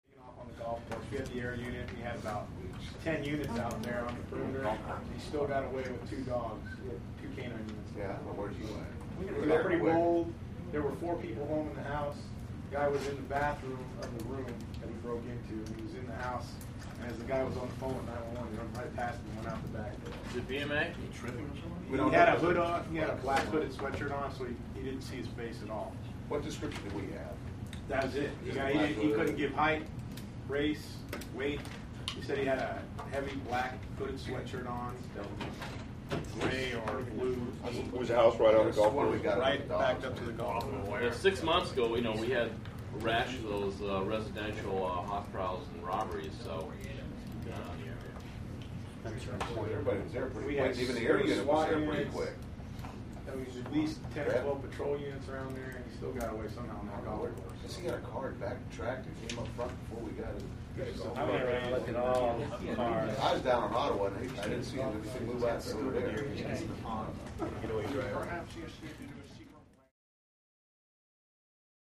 Police Station: Casual Officer Walla.